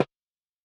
Boss Dr Pad Sample Pack_Block.wav